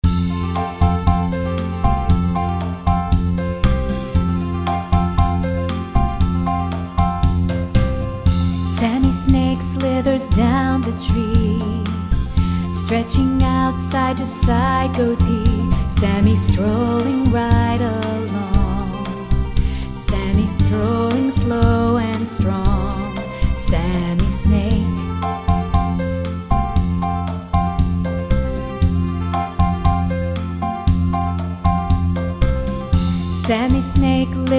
Children's Song about Snakes